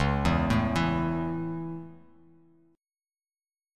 Dbm#5 chord